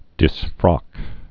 (dĭs-frŏk)